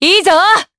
Seria-Vox_Happy4_jp.wav